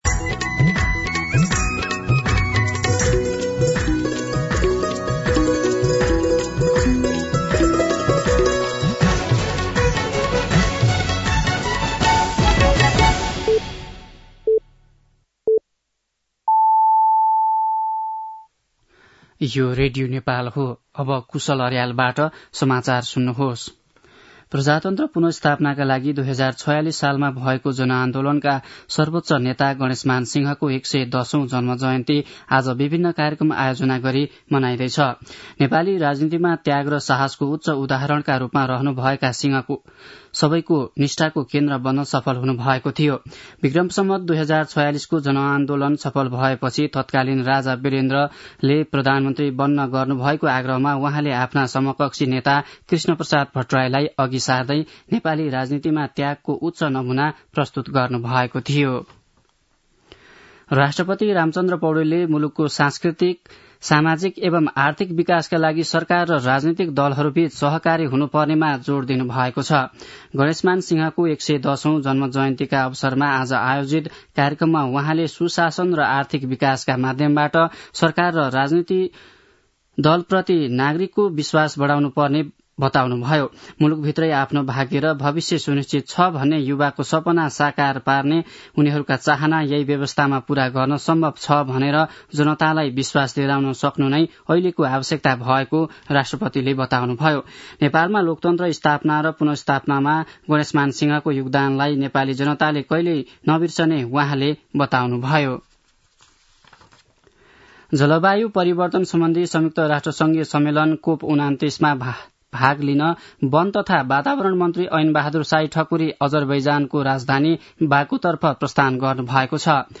दिउँसो १ बजेको नेपाली समाचार : २५ कार्तिक , २०८१
1-pm-Neoali-News.mp3